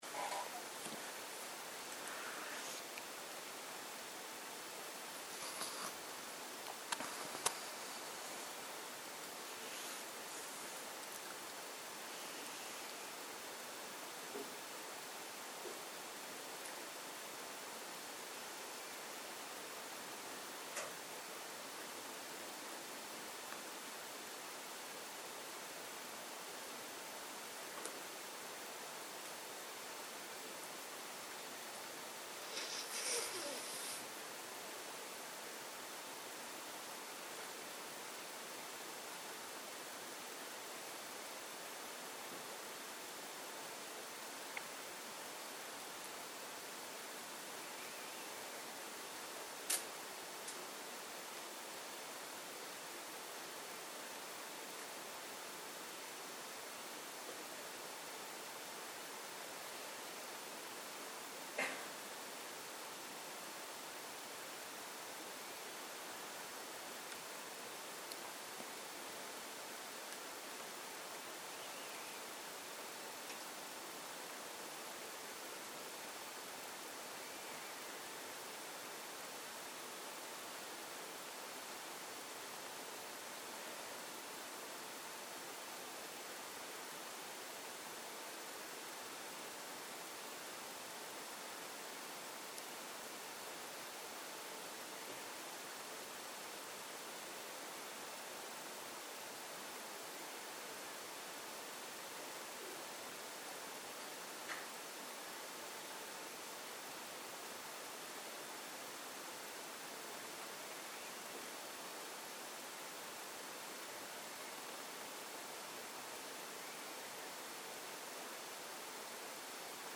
מדיטציה מונחית